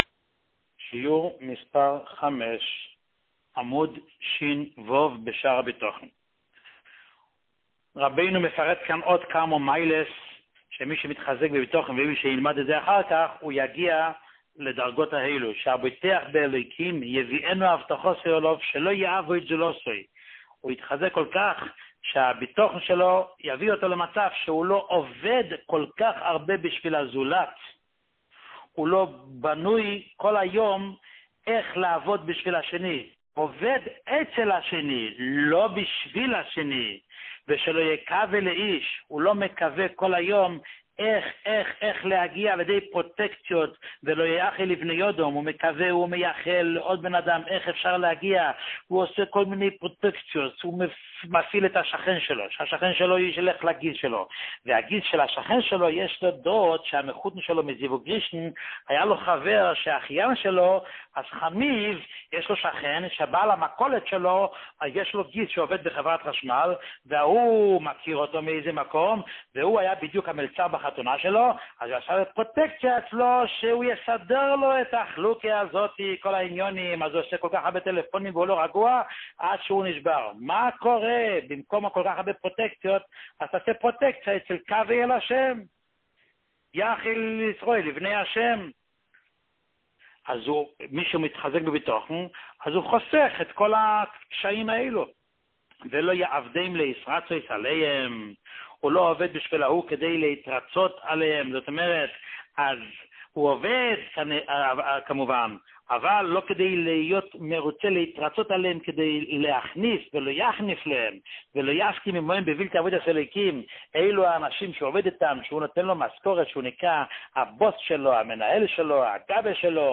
שיעור מספר 5